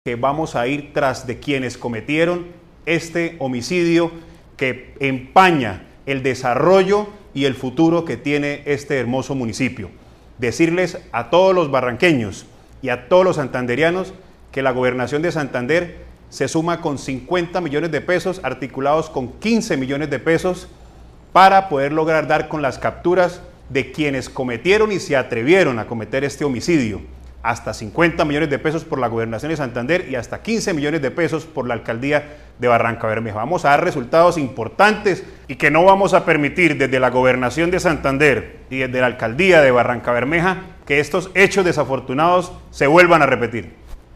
Douglas Javier Arenas, Director de Seguridad y Convivencia Ciudadana de la Gobernación de Santander